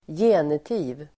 Ladda ner uttalet
Uttal: [²j'e:niti:v]